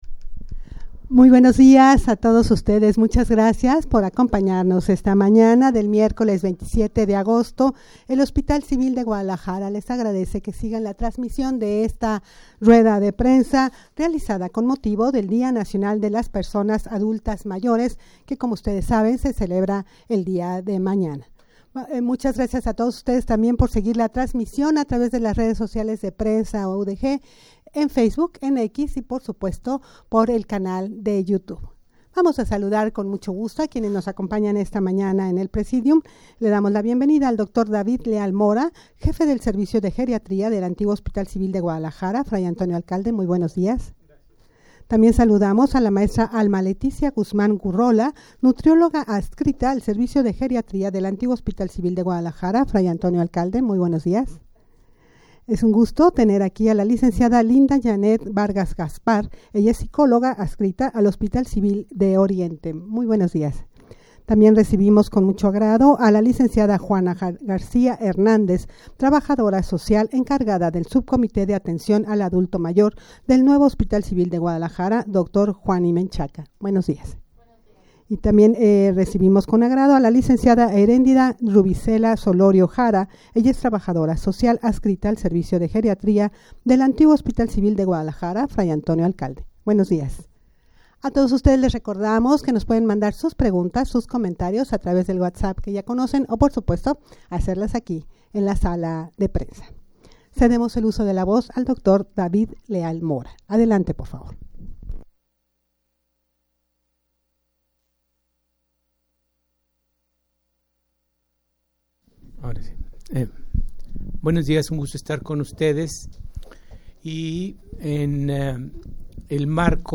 rueda-de-prensa-con-motivo-del-dia-nacional-de-las-personas-adultas-mayores-a-celebrarse-el-28-de-agosto.mp3